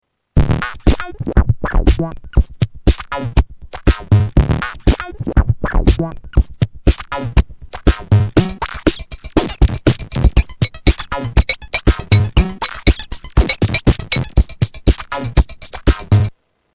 DR-55 Dr Rhythm demo 1
Tags: Boss Drum machine DR-55 Dr. Rhythm Drum machine sounds DR-55